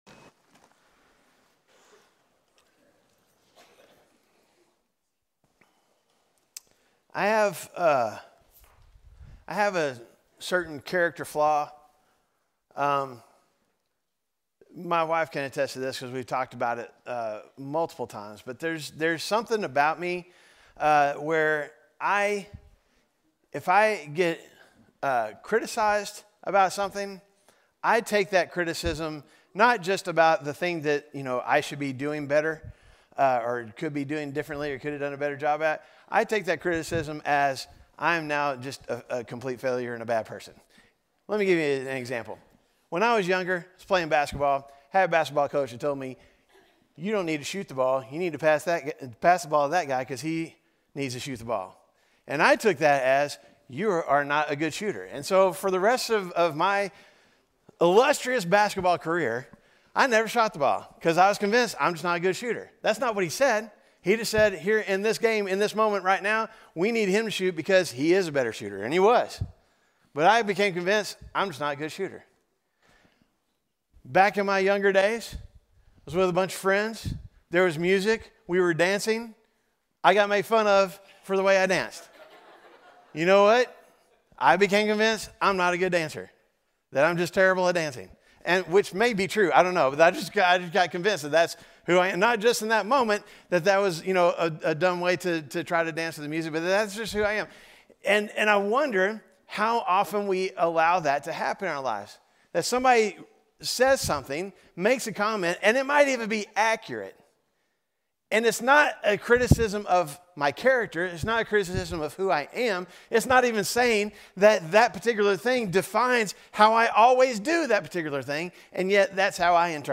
Message: “The Reluctant Prophet